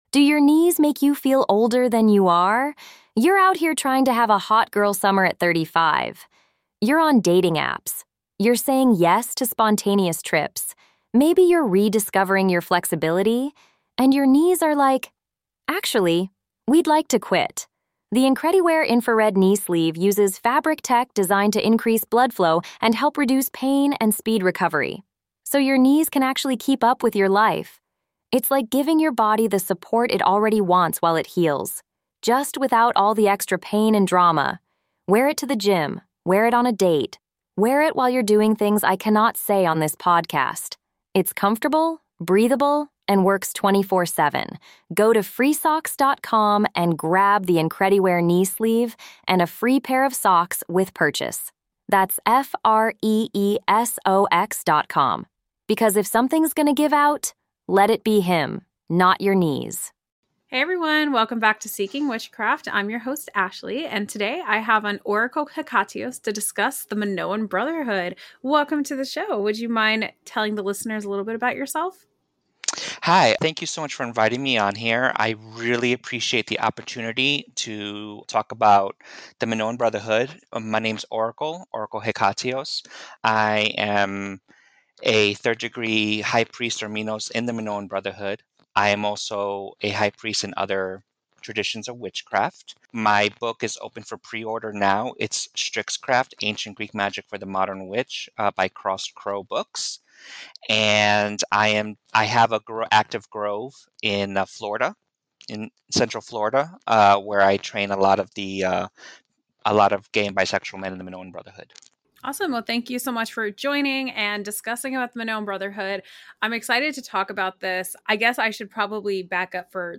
Together, they explore the origins of the Brotherhood, its values, and how it fits within Paganism and witchcraft. Whether you’re curious about queer magical traditions or looking to deepen your understanding of inclusive paths in modern spirituality, this conversation offers powerful insight and perspective.